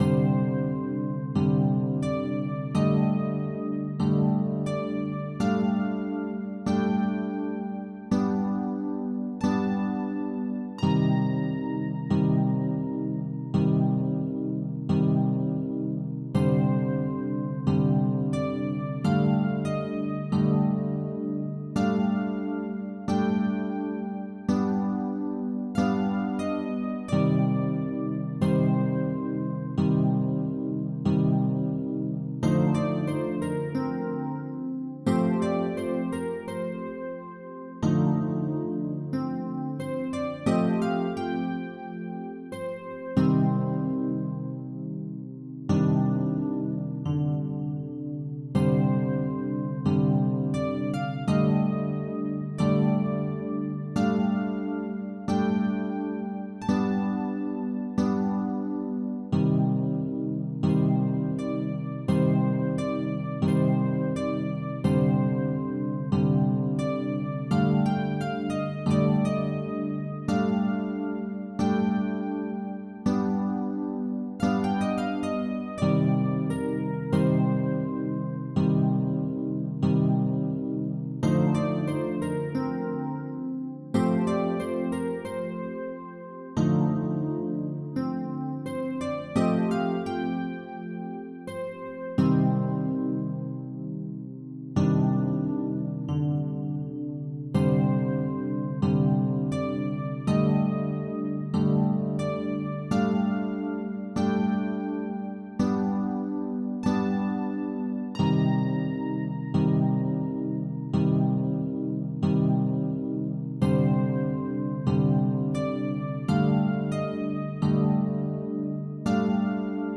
This track is a minute and a halfish long dorian loop that has random, very subtle, tempo shifts. Between 87 and 95 bpm done every 8th note. It's meant to humanize the track a bit more than some of the syncopation I already put in the melody.
unsteady_pianist.ogg